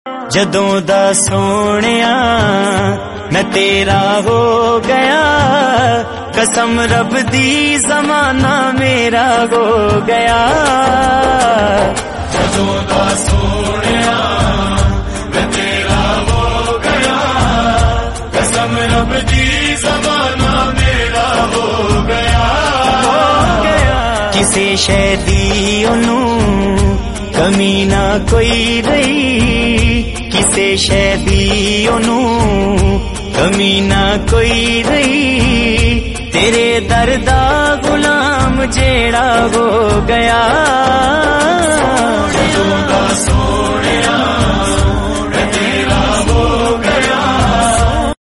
Punjabi Naat